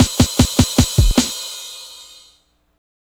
drums07.wav